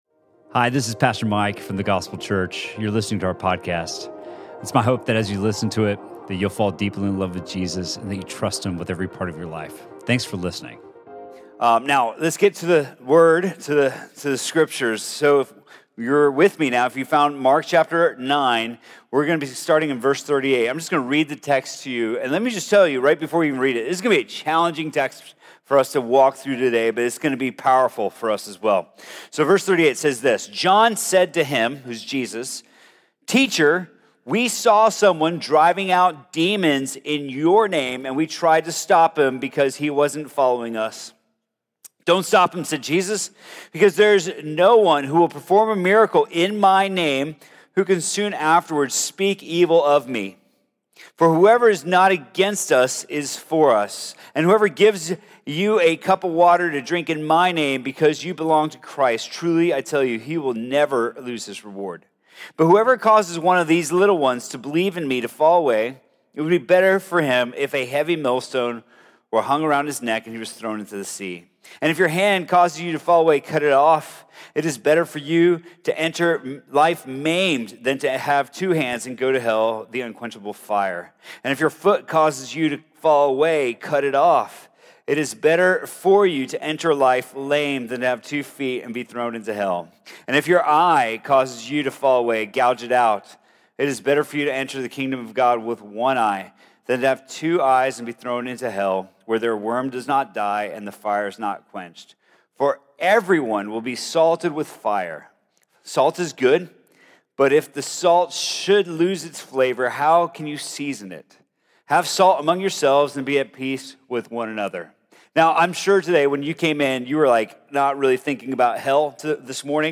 Sermon from The Gospel Church on August 18th, 2019.